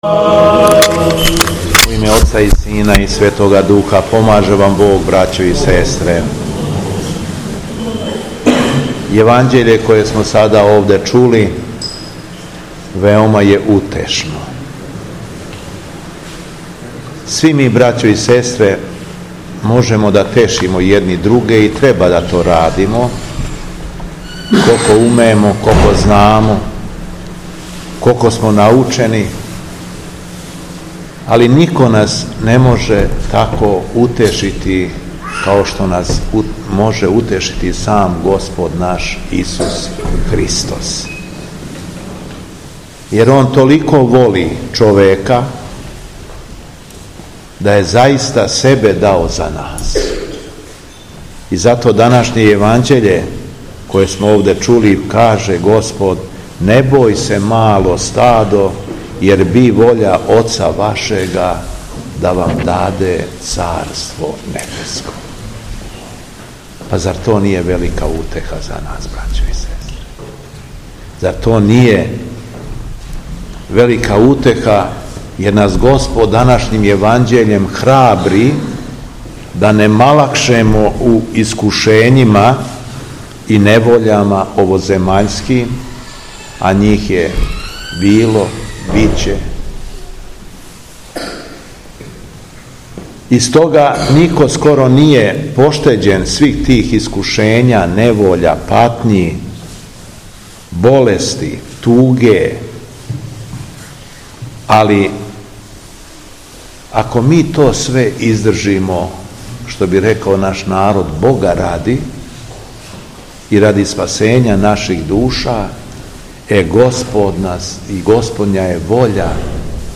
АРХИЈЕРЕЈСКА ЛИТУРГИЈА У РОГАЧИ - Епархија Шумадијска
Беседа Његовог Преосвештенства Епископа шумадијског г. Јована
После прочитаног јеванђелског зачала Преосвећени Владика се обратио сабраном народу рекавши: